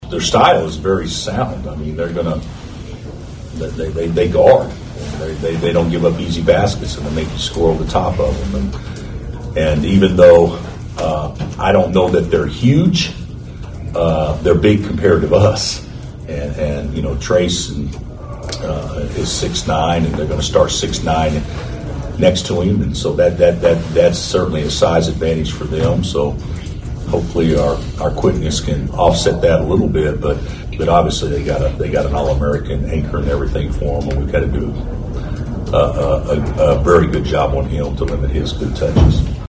Coach Bill Self says this will be a good test for the Jayhawks.
12-17-bill-self.mp3